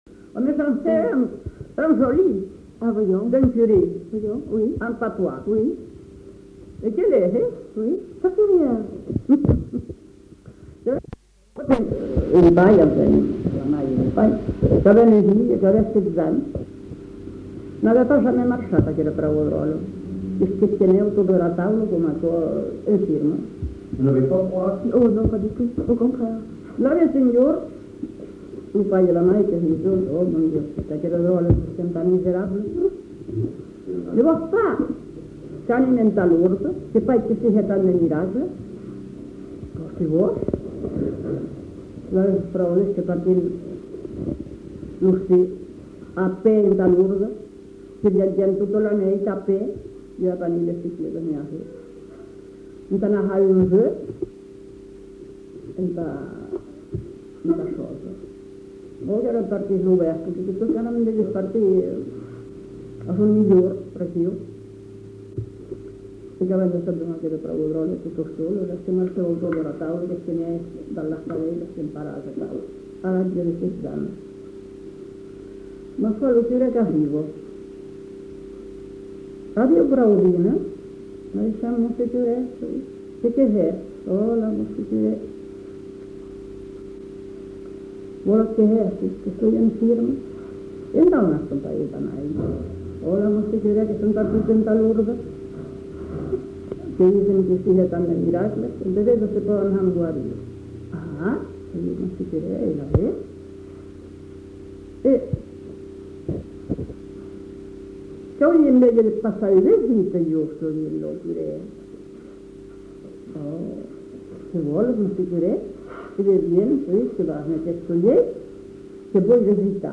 Aire culturelle : Marsan
Lieu : [sans lieu] ; Landes
Genre : conte-légende-récit
Effectif : 1
Type de voix : voix de femme
Production du son : parlé